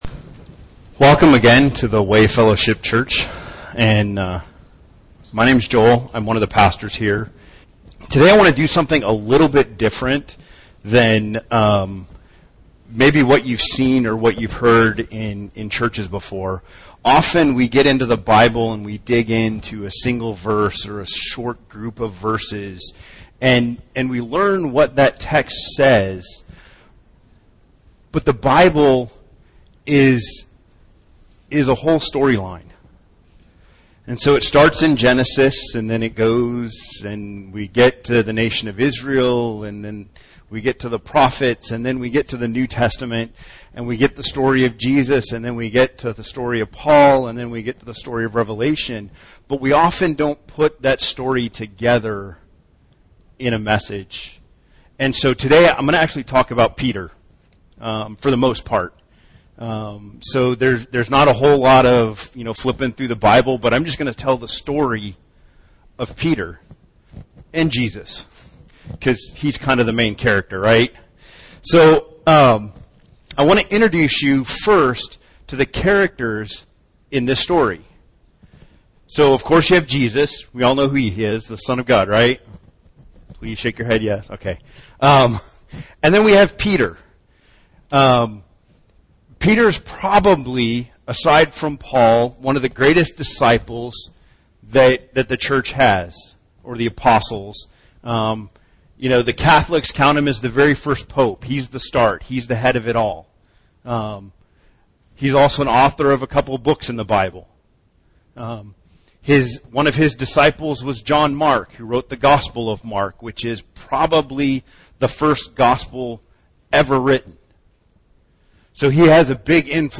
What does the Bible teach us about discipleship and its intersection within community? This is stand alone message about our call to be discipled and disciple others in and through community.